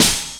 Snare_1_(Cymbal_Steppa).wav